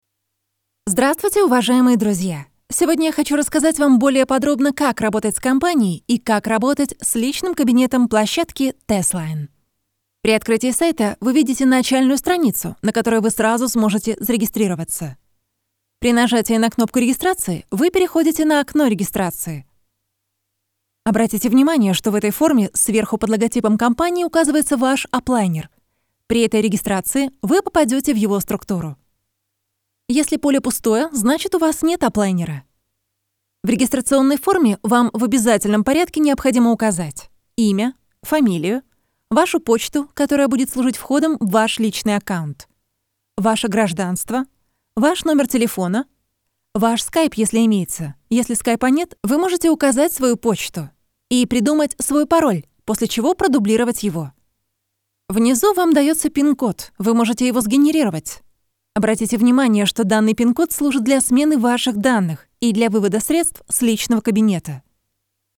Sprechprobe: Industrie (Muttersprache):
voice-over actress with a voice that sounds friendly and sincere her voice is a happy mix of corporate style and girl next-door authenticity.
young and fresh and to the middle age from 18 - 40.